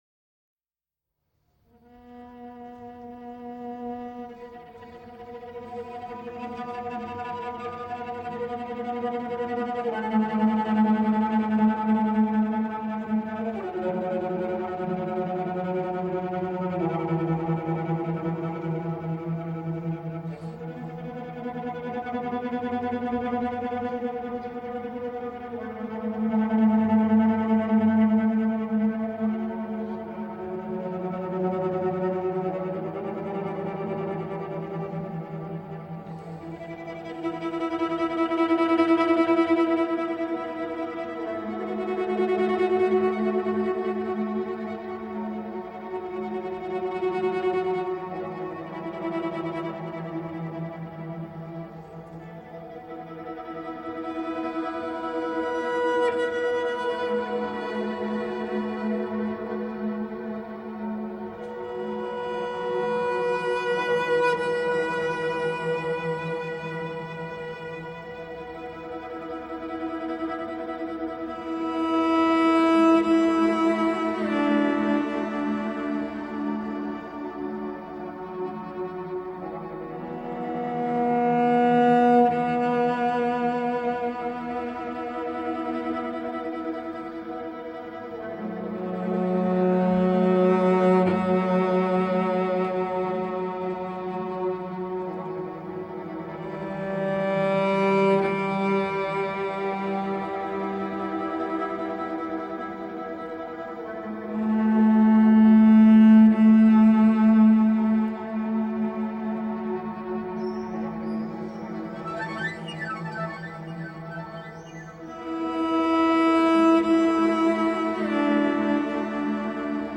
New age meets indian cello.
Tagged as: New Age, Other, Cello, Massage